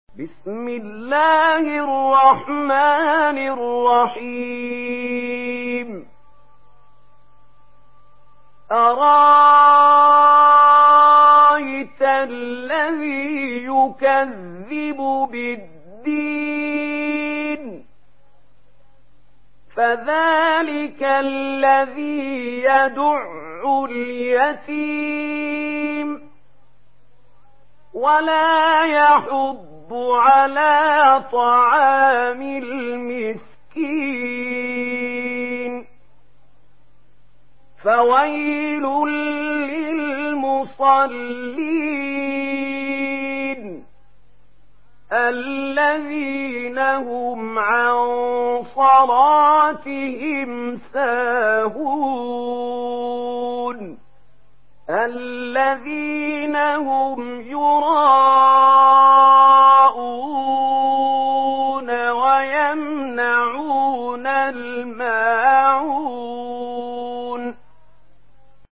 سورة الماعون MP3 بصوت محمود خليل الحصري برواية ورش
مرتل ورش عن نافع